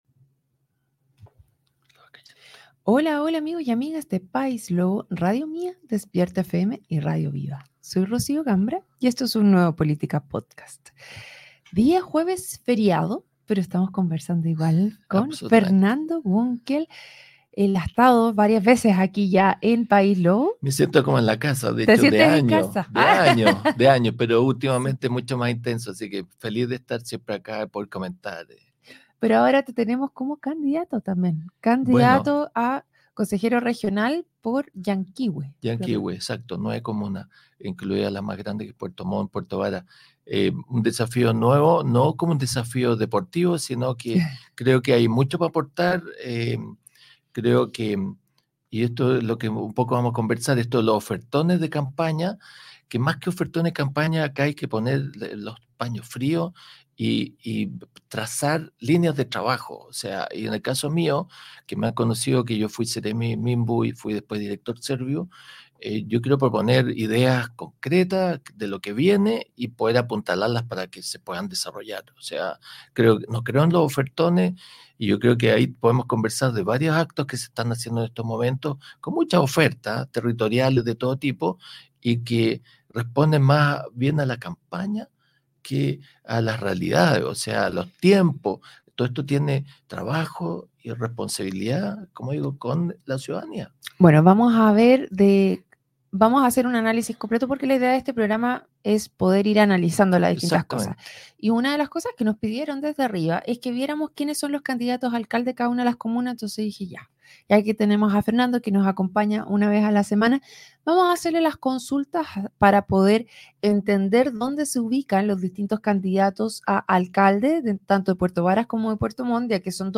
En un nuevo episodio del "Política Podcast", transmitido a través de Paislobo, Radio Mía Despierta FM y Radio Viva, se discutieron temas claves sobre las próximas elecciones municipales en las comunas de Puerto Varas y Puerto Montt.